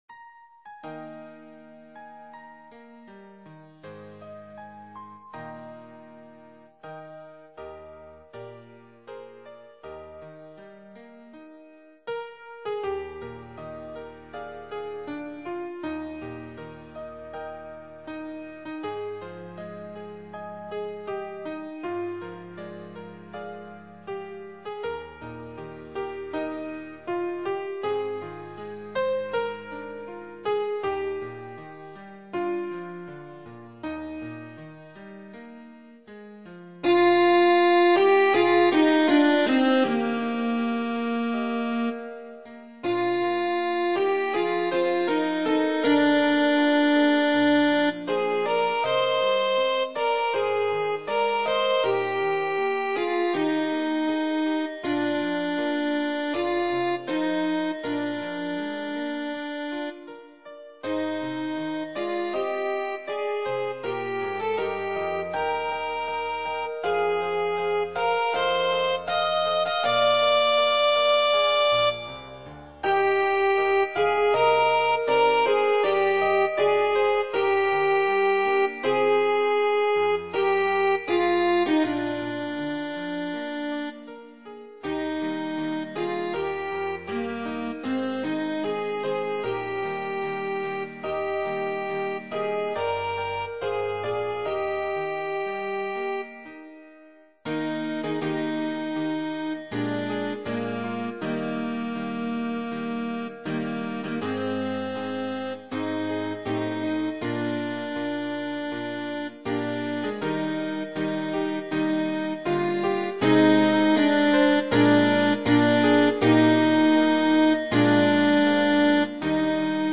Sop..wma